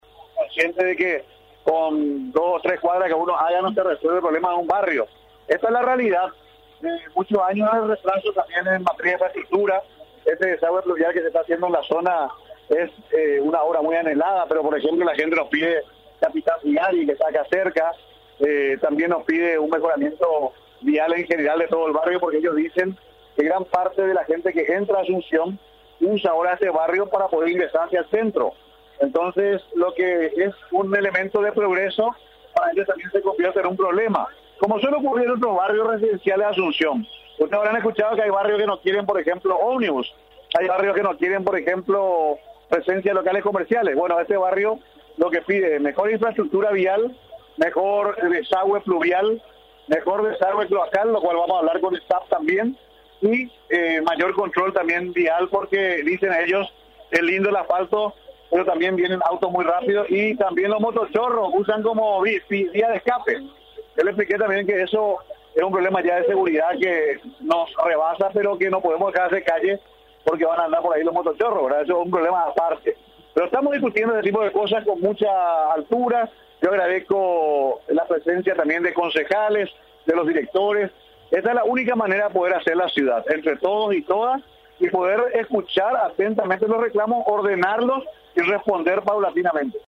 50-INT.-MARIO-FERREIRO.mp3